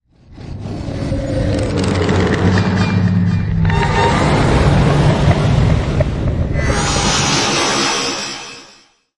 科幻的声音效果 (6)
从ZOOM H6录音机和麦克风Oktava MK01201领域录制的效果，然后处理。
Tag: 未来 托管架 无人驾驶飞机 金属制品 金属 过渡 变形 可怕 破坏 背景 游戏 黑暗 电影 上升 恐怖 开口 命中 噪声 转化 科幻 变压器 冲击 移动时 毛刺 woosh 抽象 气氛